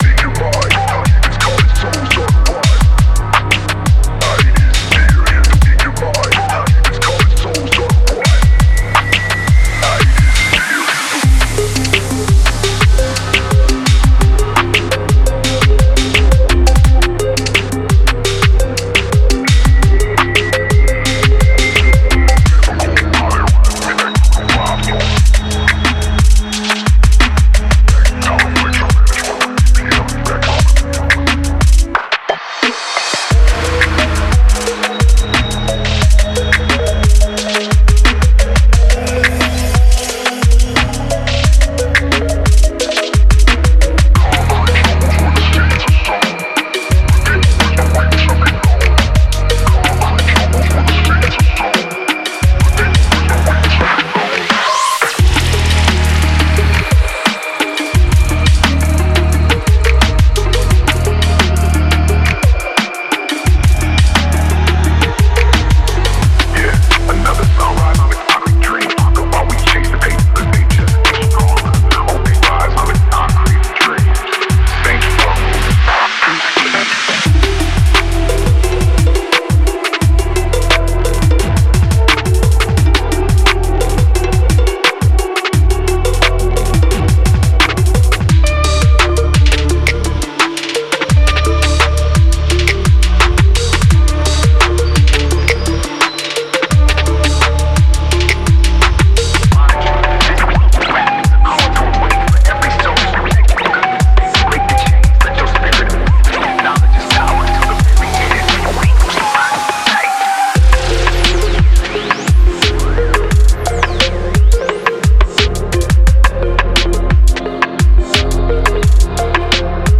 Genre:Trap
荒々しいドリフトフォンクのドラムパートとパーカッション
ディストーションがかかった808と攻撃的なベースワンショット
メンフィススタイルのカウベル、リード、ダークメロディーとテクスチャ
刻まれたボーカル、フレーズ、FXで雰囲気とグルーヴを演出
デモサウンドはコチラ↓